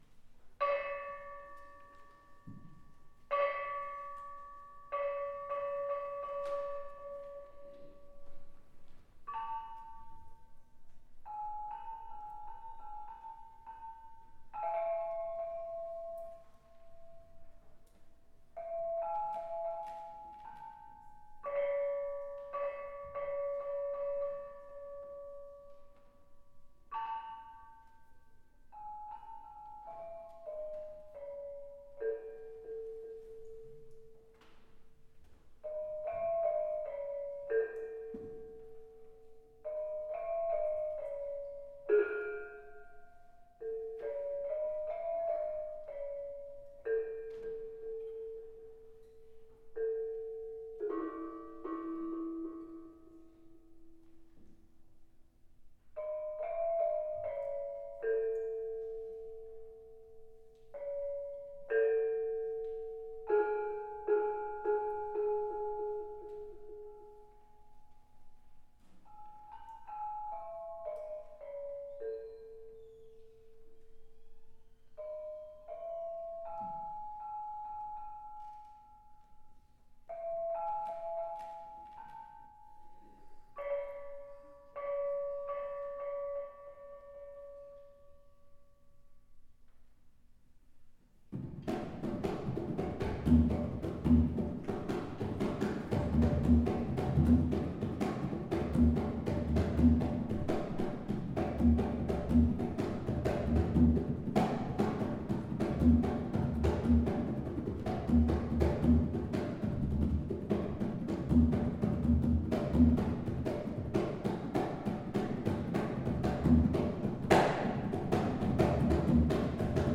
Balinese gamelan